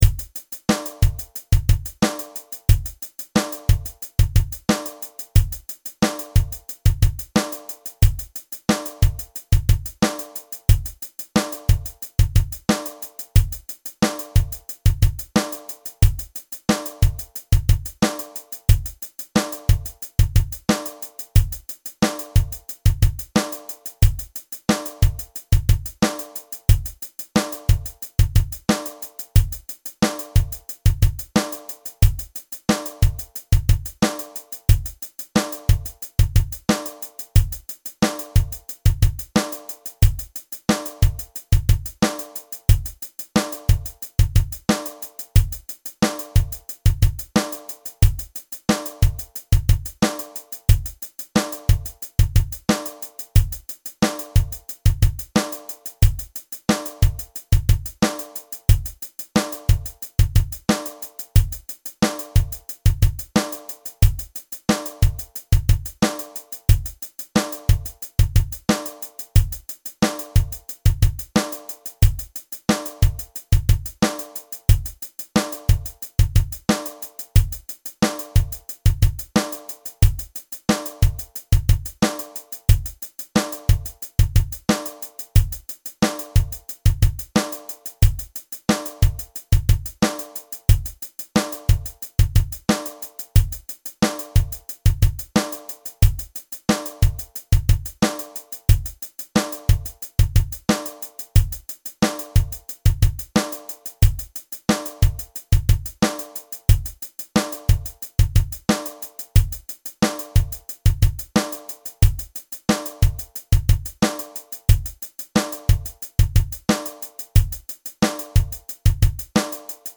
Song Drum Track